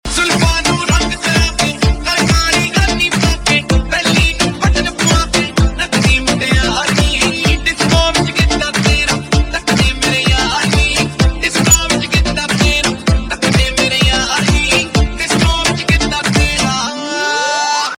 Punjabi Mp3 Tone